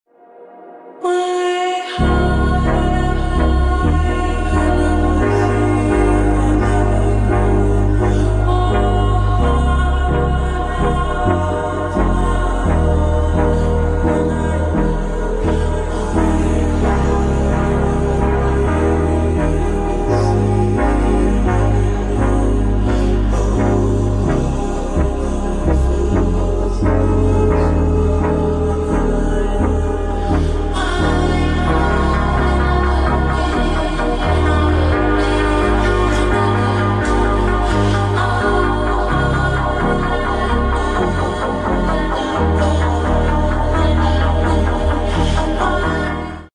Stagecoach Hull on loan to Skegness 19211 arriving onto Richmond Drive in Skegness on a 2a Town Circular